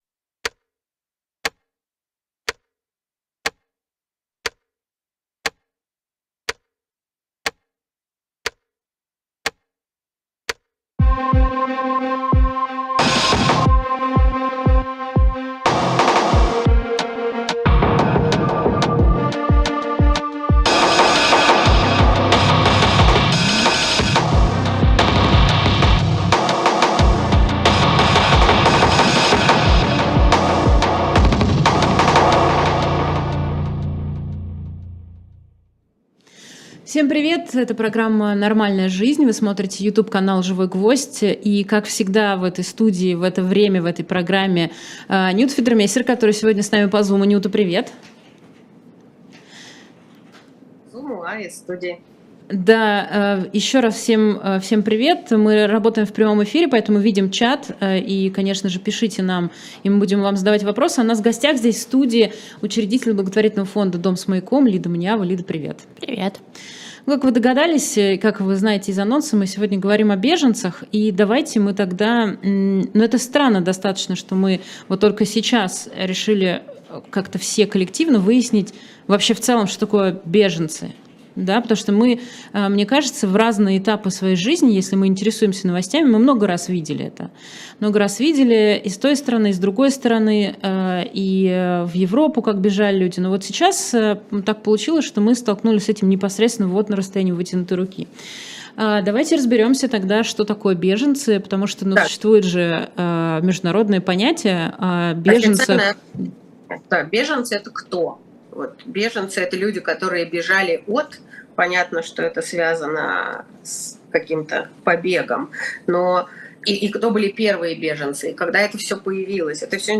В эфире Лида Мониава, учредитель благотворительного фонда "Дом с маяком".